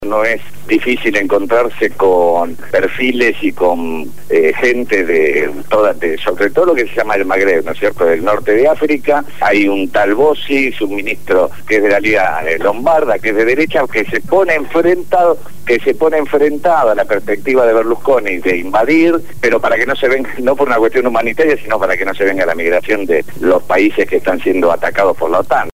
habló desde Italia y dio un completo informe de la situación crítica que vive el país europeo.